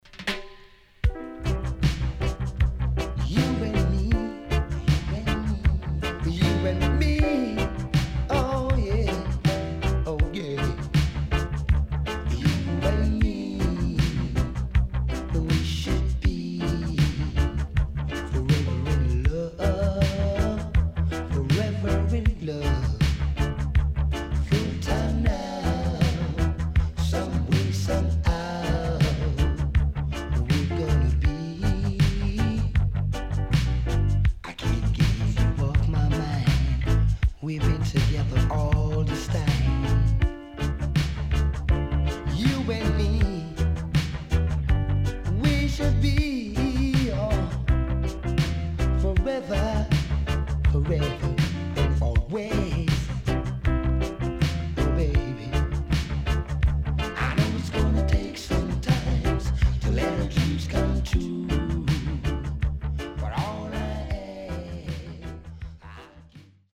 HOME > LP [DANCEHALL]
SIDE A:少しチリノイズ入りますが良好です。